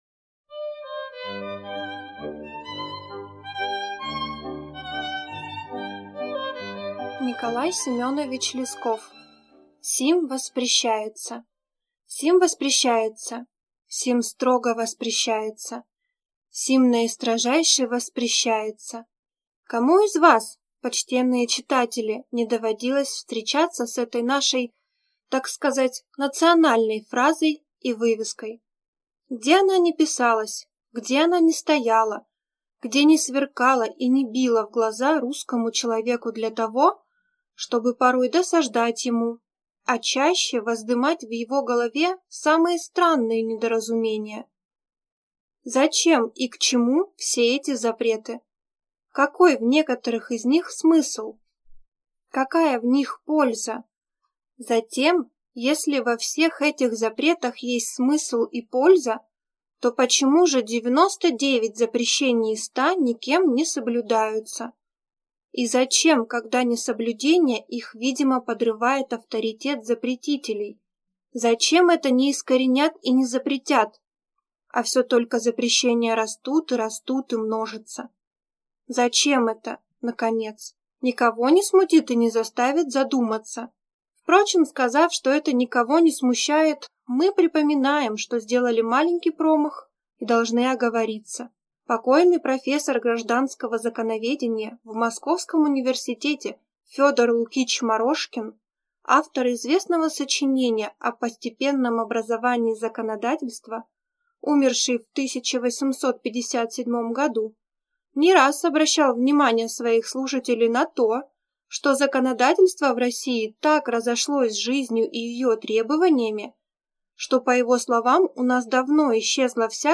Аудиокнига Сим воспрещается…